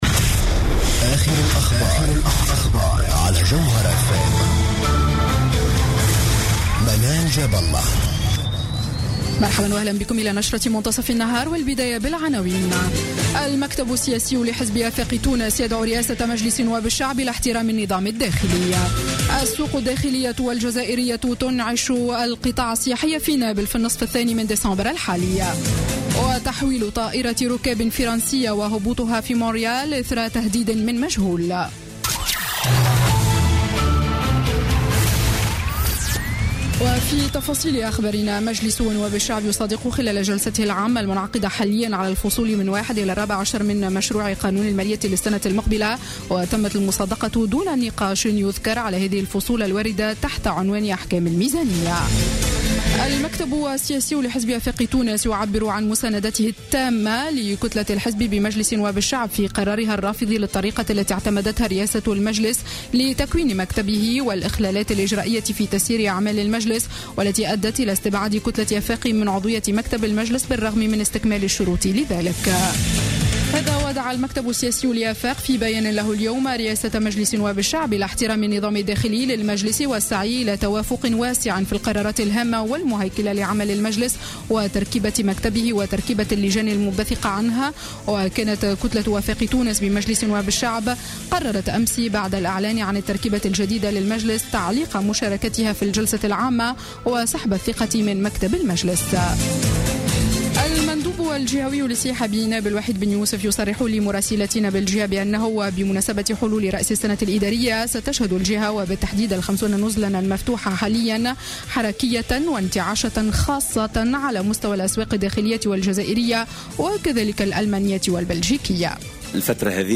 نشرة أخبار منتصف النهار ليوم الثلاثاء 08 ديسمبر 2015